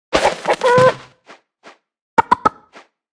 Sfx_Anim_Base_Chicken.wav